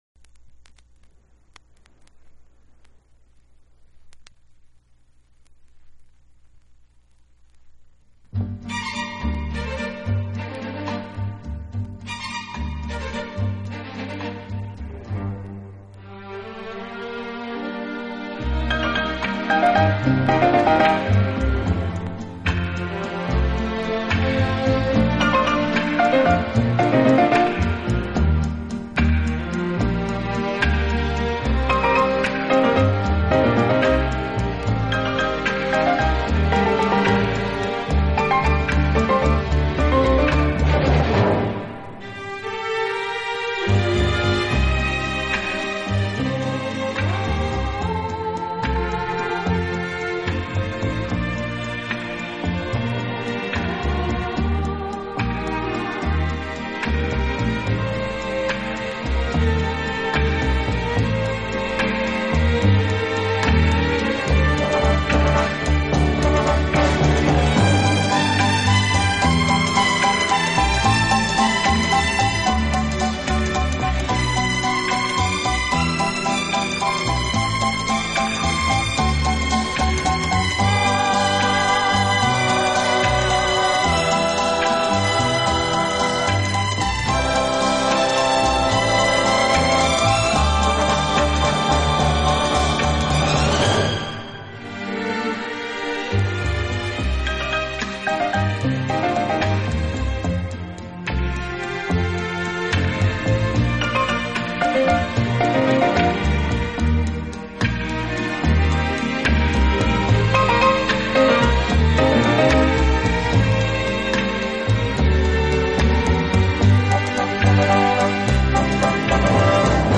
到深蘊其中的法式浪漫情怀。